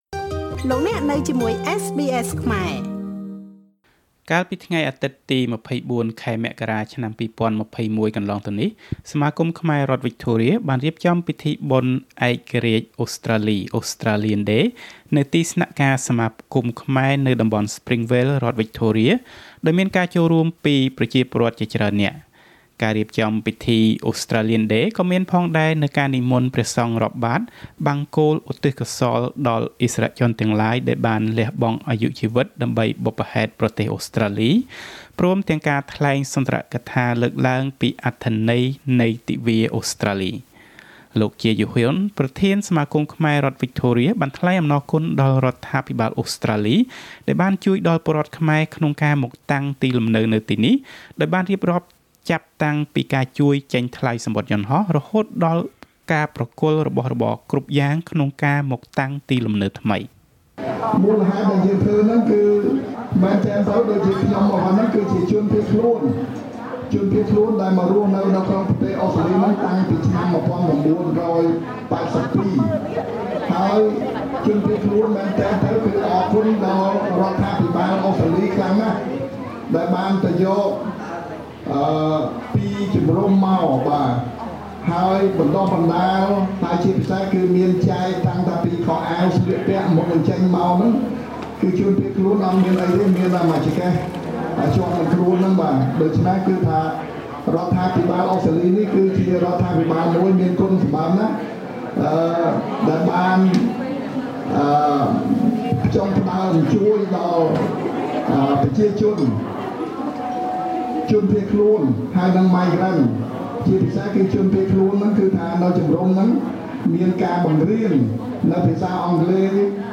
Australia Day ceremony at Cambodia Association of Victoria 2021 Source: SBS Khmer